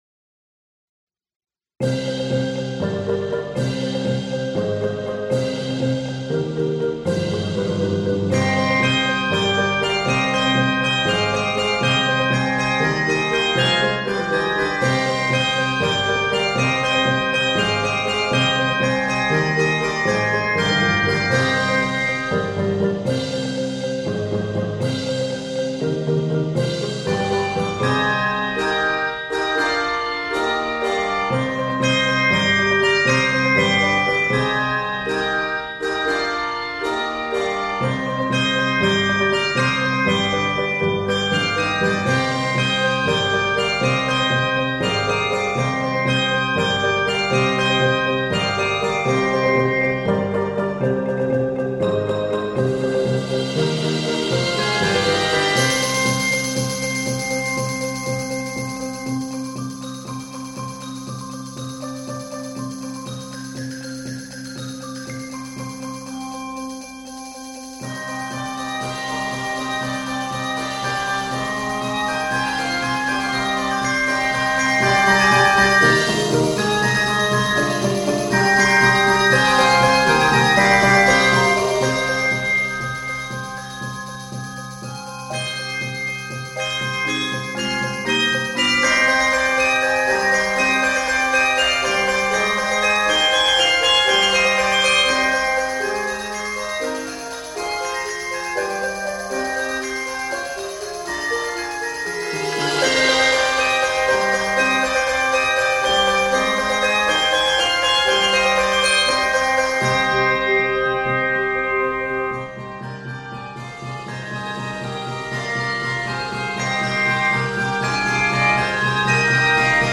Review: Talk about a show stopper!
is scored in c minor